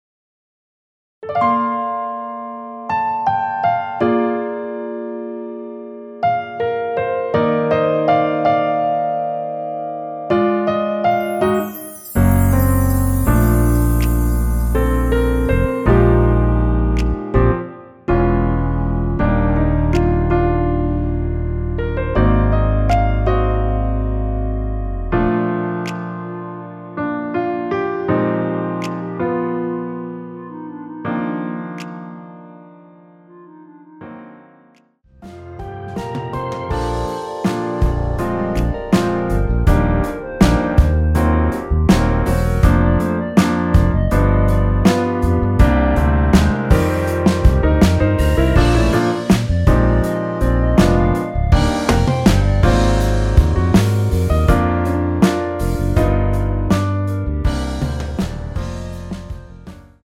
라이브 하시기 좋게 노래 끝나고 바로 끝나게 4분 36초로 편곡 하였습니다.(코러스 MR 미리듣기 참조)
원키에서(-2)내린 멜로디 포함된 MR입니다.
Bb
앞부분30초, 뒷부분30초씩 편집해서 올려 드리고 있습니다.
(멜로디 MR)은 가이드 멜로디가 포함된 MR 입니다.